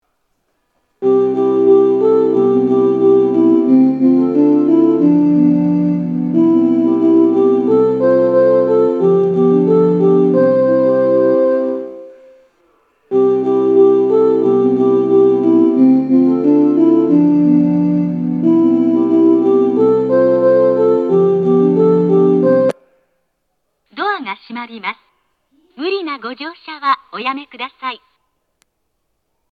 発車メロディー
1.9コーラスです!こちらも2コーラス目に入りやすいです。